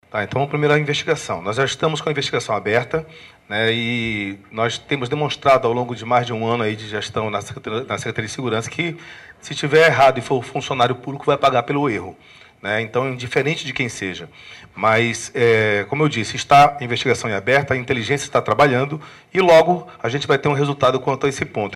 A declaração foi feita nesta quarta-feira (29) durante coletiva de imprensa no Centro Integrado de Comando e Controle (CICC), onde foram anunciadas medidas para coibir a prática.